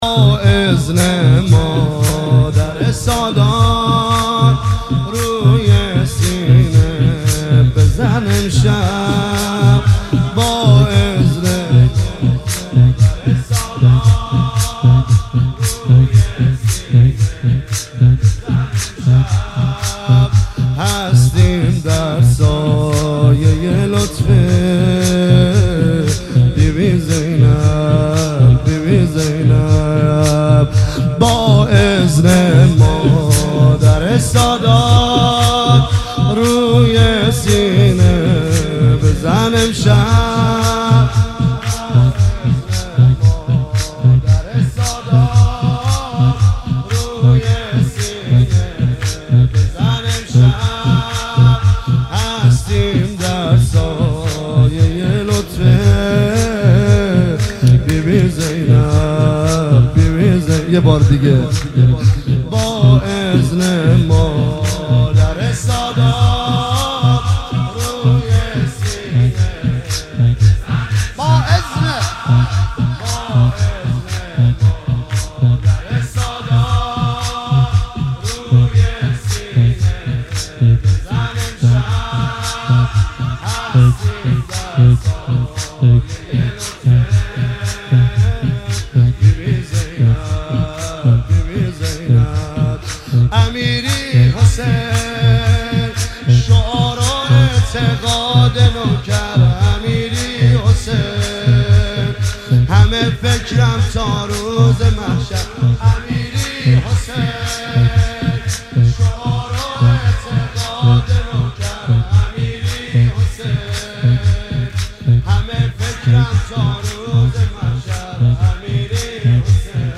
مراسم شب پنجم محرم ۱۳۹۷
زمینه با اذن مادر سادات